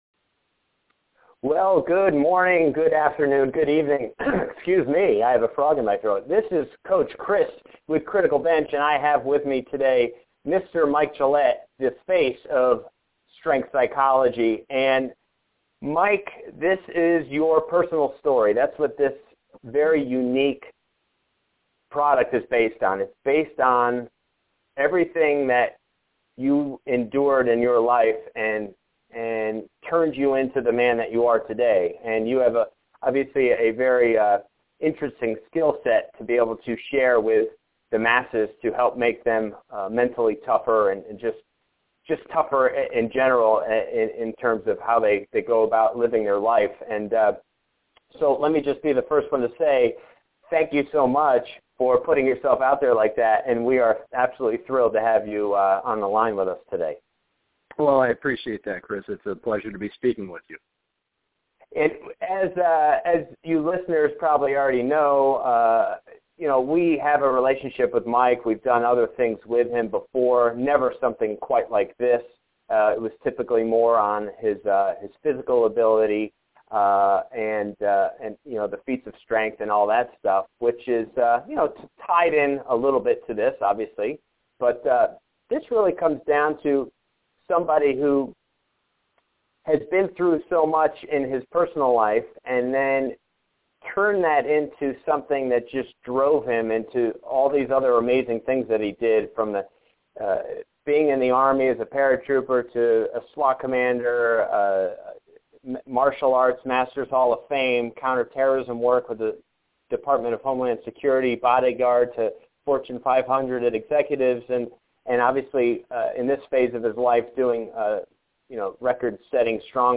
Free Audio Interview